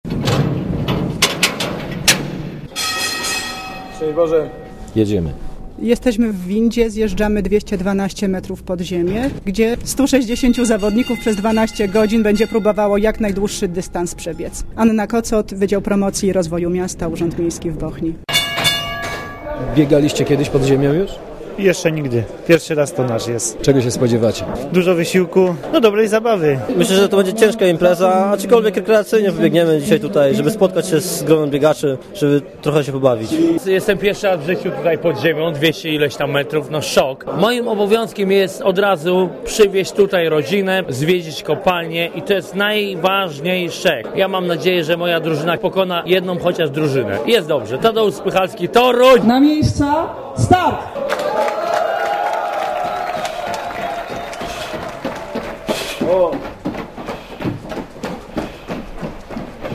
Mówią uczestnicy podziemnej sztafety